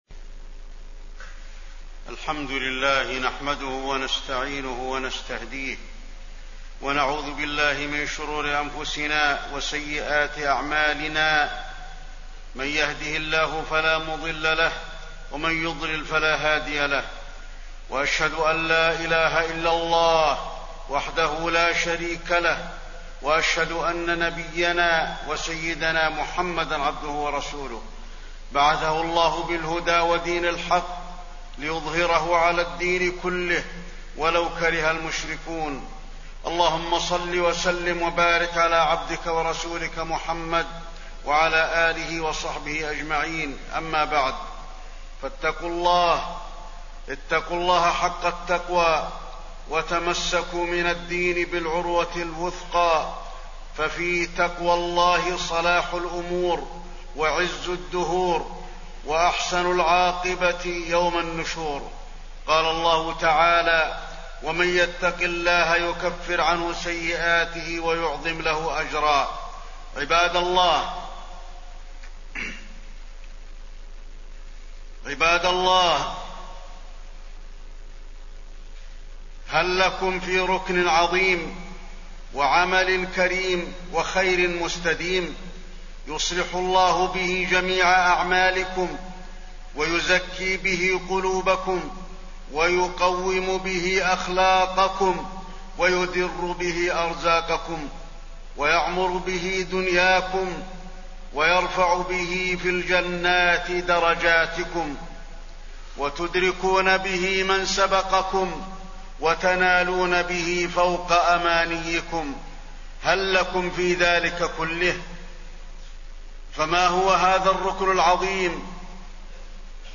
تاريخ النشر ٢٠ شوال ١٤٣٠ هـ المكان: المسجد النبوي الشيخ: فضيلة الشيخ د. علي بن عبدالرحمن الحذيفي فضيلة الشيخ د. علي بن عبدالرحمن الحذيفي الصلاة